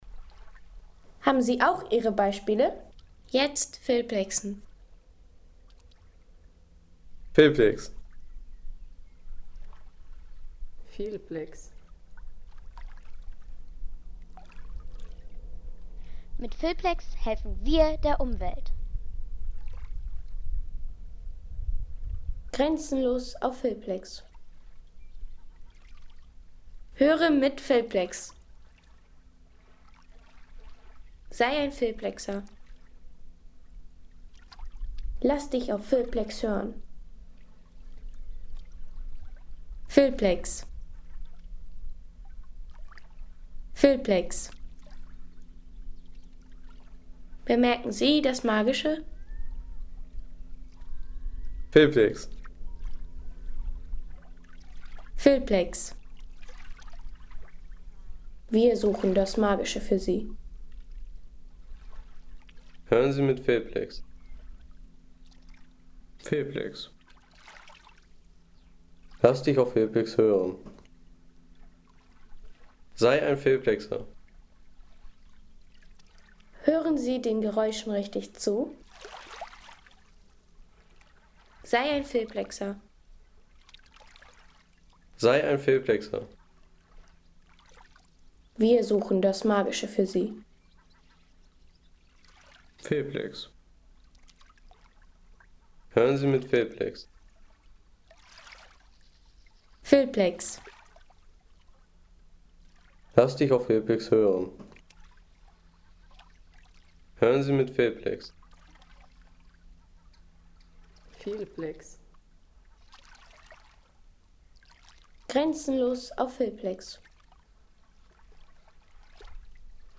Ostseestrand in Loddenhøj
Das Rauschen der Wellen, das Schreien der Möwen und das Rauschen der ... 3,50 € Inkl. 19% MwSt.